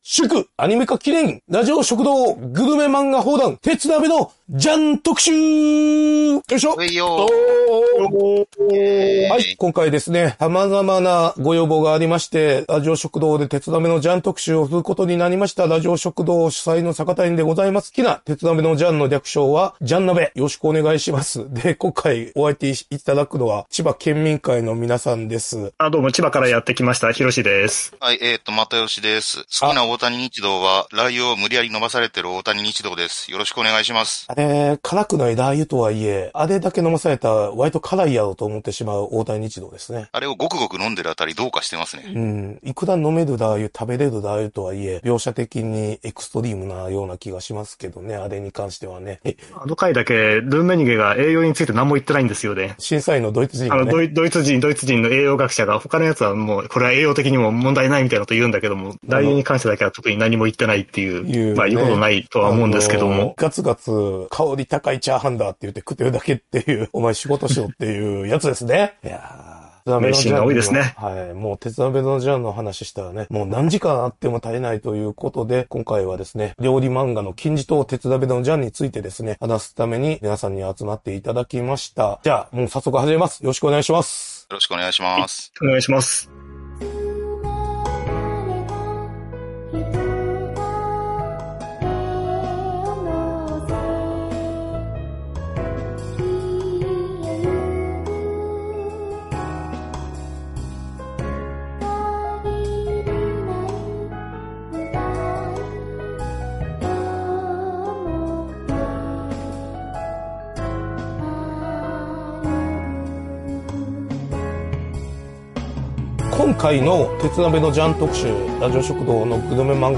月一回、番組内でコメントにお返事していきます。